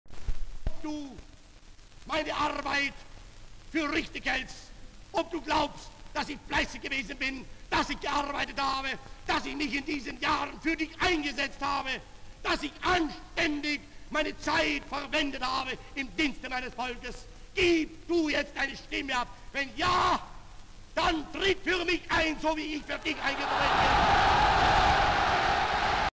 Adolf-Hitler-Speech-at-Krupp-Factory-in-Germany-1935-British-Pathe.mp3